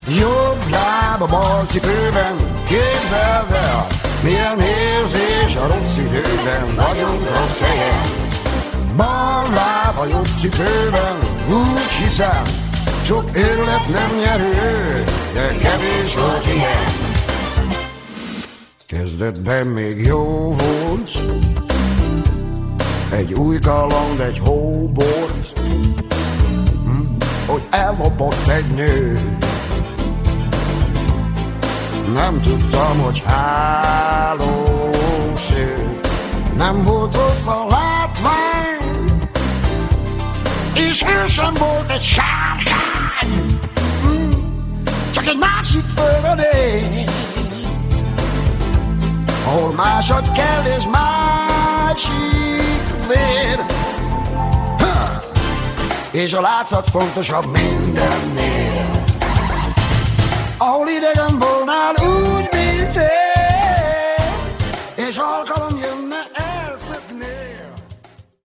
Lattmann Béla: Bass